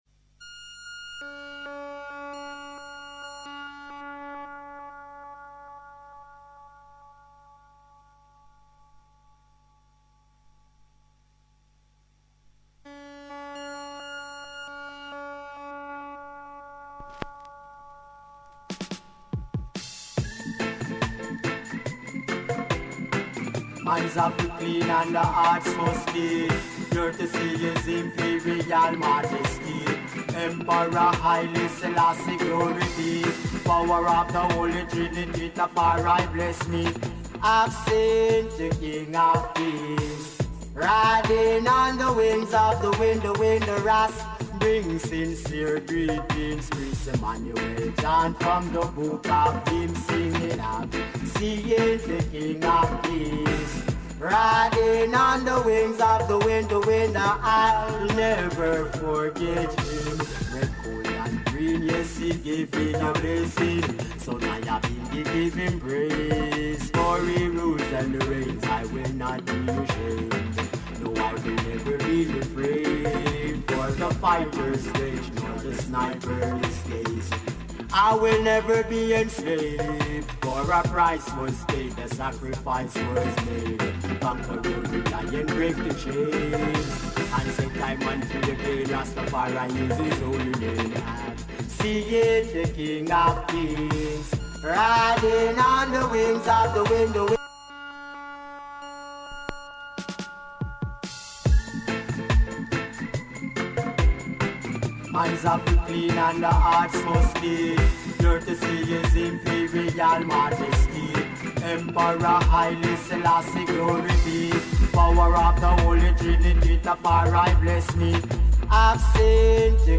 Strictly Vinyl Selection !!!!!!!!!